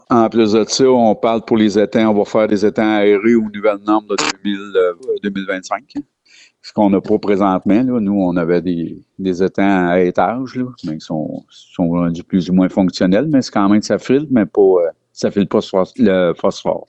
Le maire a également mentionné d’autres travaux qui seront effectués.